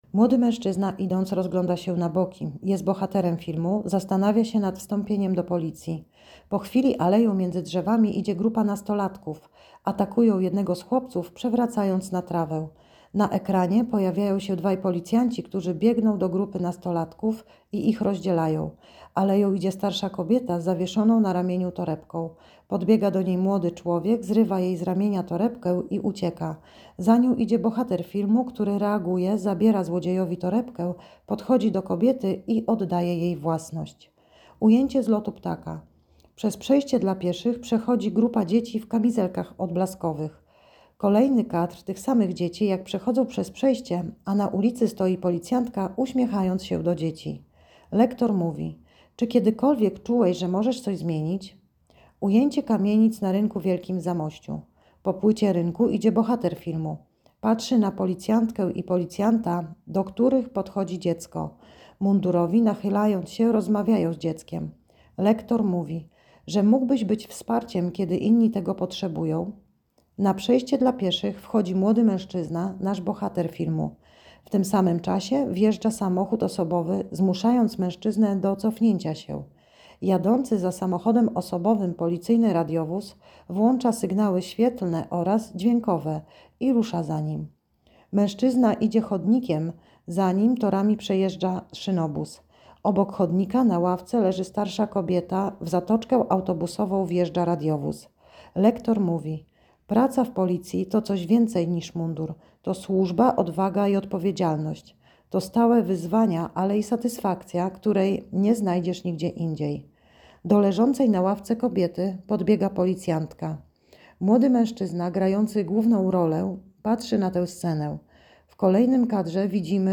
Nagranie audio Audiodeskrypcja spotu DOŁĄCZ DO NAS!